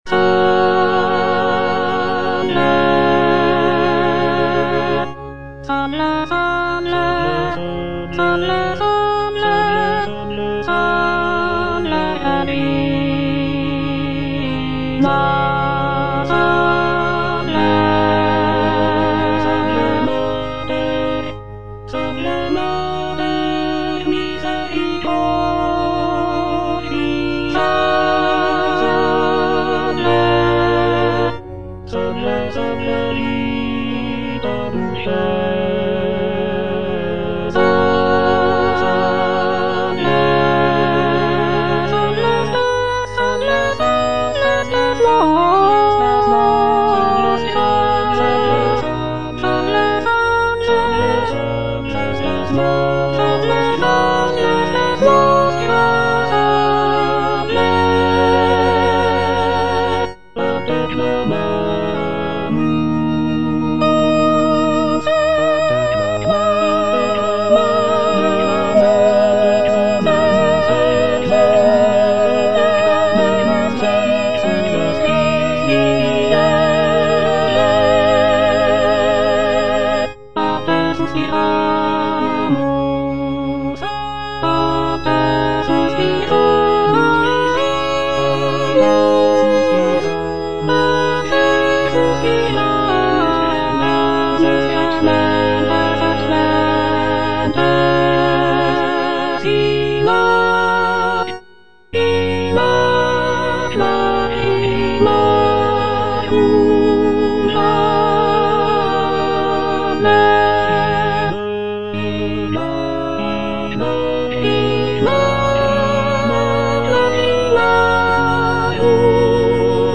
G.F. SANCES - SALVE, REGINA (A = 415 Hz) Soprano (Emphasised voice and other voices) Ads stop: auto-stop
This piece is a setting of the traditional Latin Marian hymn "Salve Regina" and is performed in a lower pitch of A = 415 Hz, which was common in the Baroque era. The work features rich harmonies, expressive melodies, and intricate vocal lines, showcasing Sances' skill as a composer of sacred music.